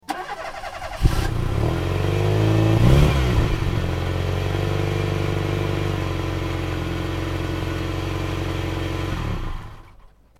دانلود آهنگ ماشین 7 از افکت صوتی حمل و نقل
جلوه های صوتی
دانلود صدای ماشین 7 از ساعد نیوز با لینک مستقیم و کیفیت بالا